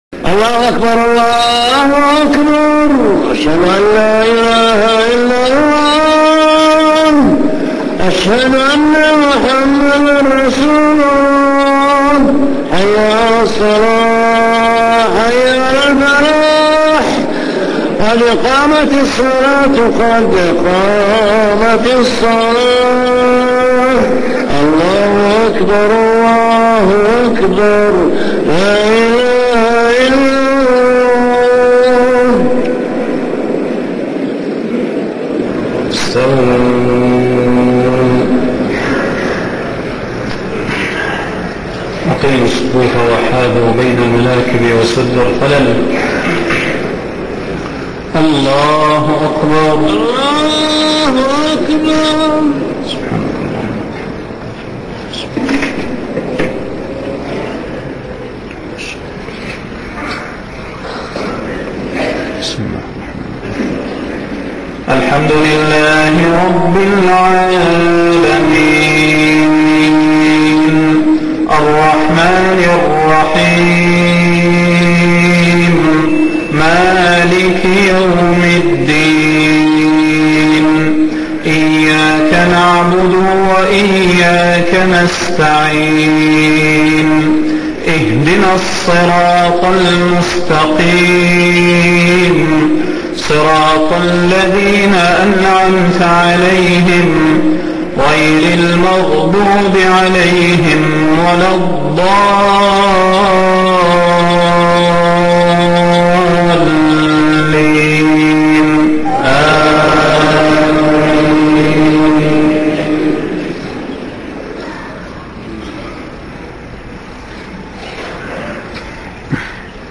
صلاة المغرب 5 محرم 1430هـ سورتي الزلزلة والعاديات > 1430 🕌 > الفروض - تلاوات الحرمين